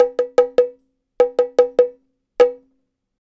Bongo Fill 07.wav